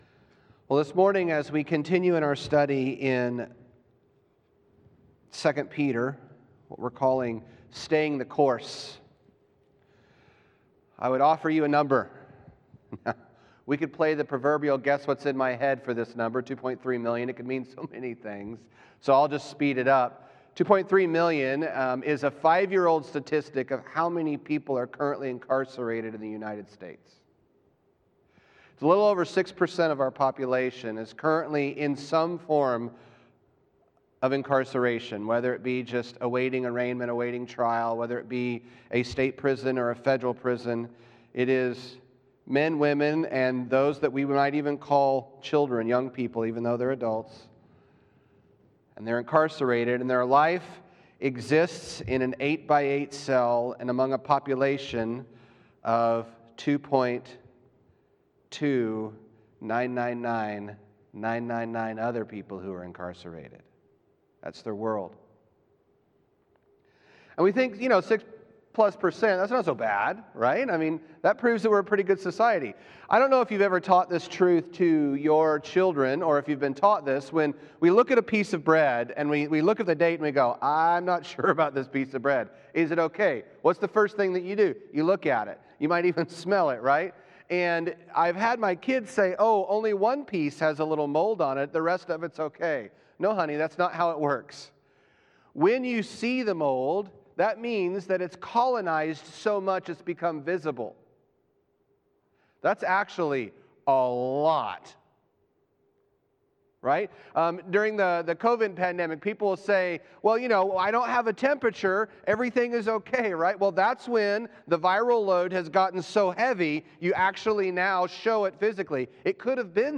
Sermon Series: 2 Peter – Truth or Death